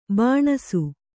♪ bāṇasu